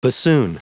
Prononciation du mot bassoon en anglais (fichier audio)
Prononciation du mot : bassoon